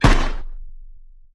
Erekir turret SFX (of varying quality)
shootAltLong.ogg